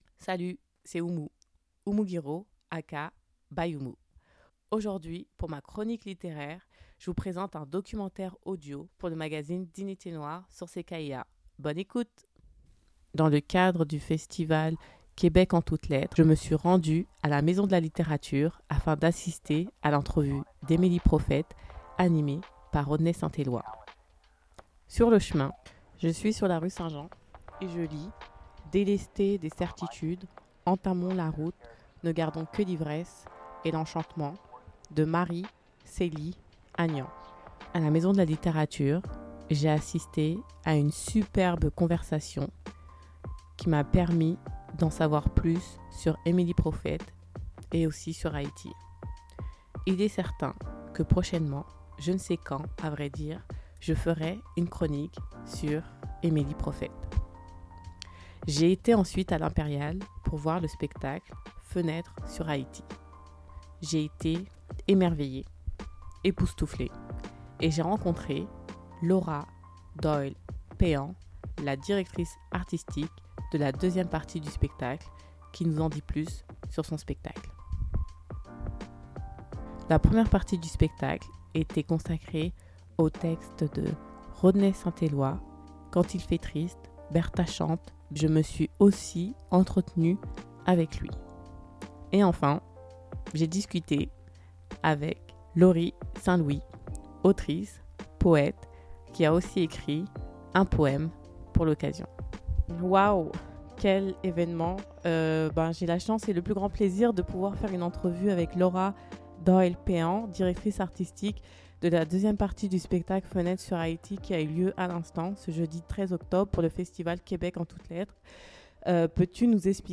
Aujourd’hui pour ma chronique littéraire, je vous présente un documentaire audio mon premier documentaire audio pour le magazine Dignité noire sur CKIA, 88.3.